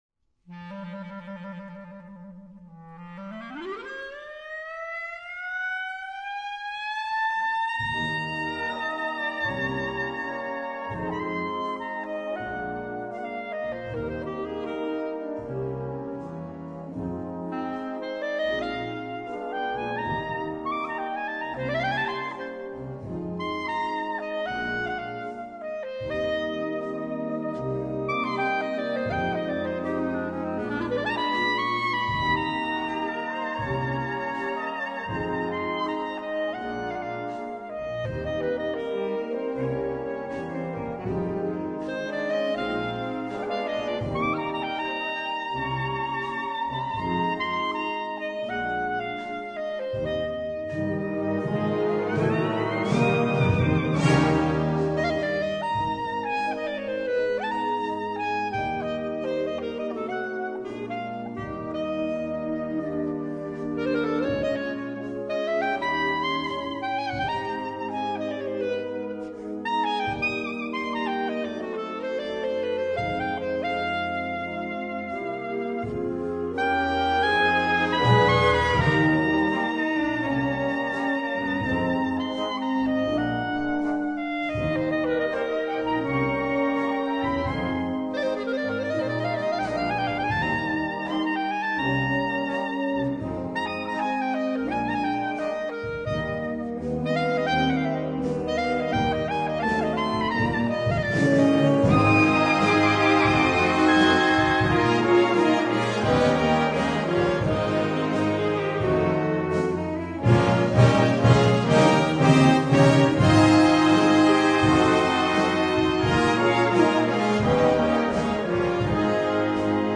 Clarinetto e banda
Clarinetto e pianoforte